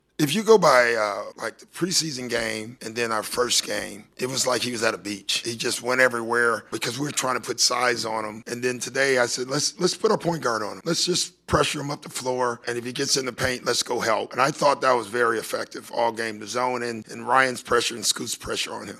Rivers talked about defending Cunningham.